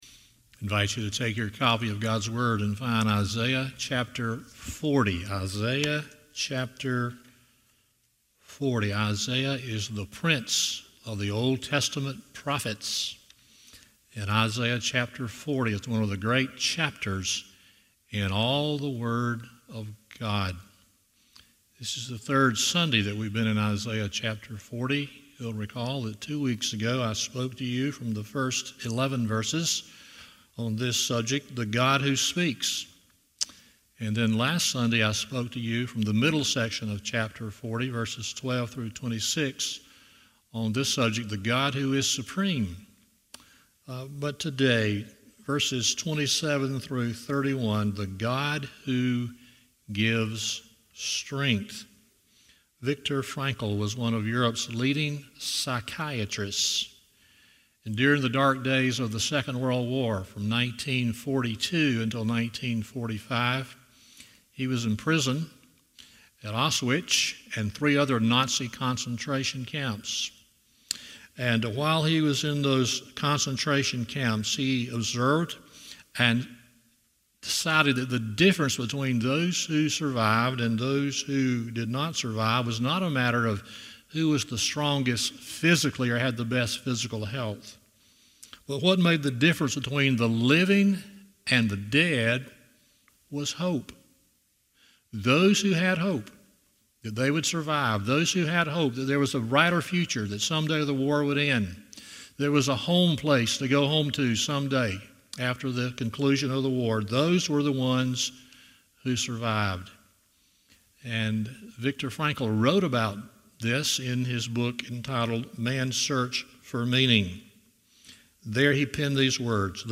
Isaiah 40:27-31 Service Type: Sunday Morning 1.